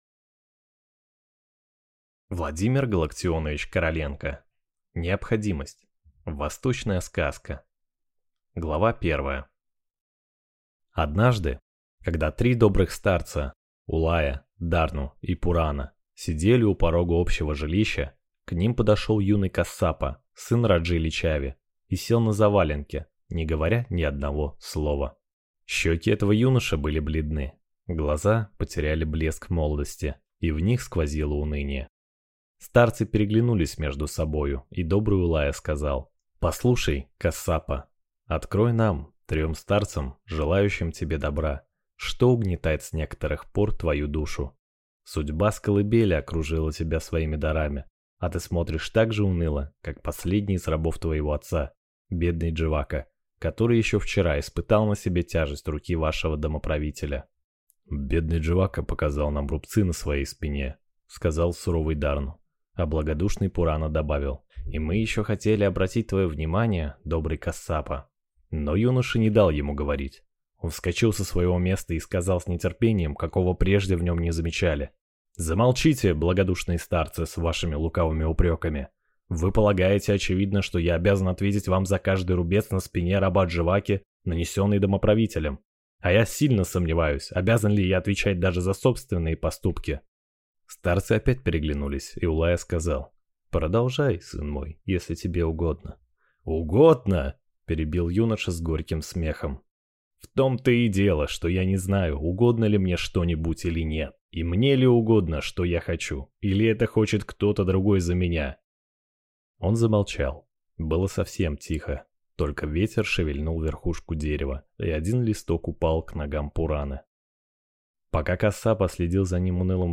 Аудиокнига Необходимость | Библиотека аудиокниг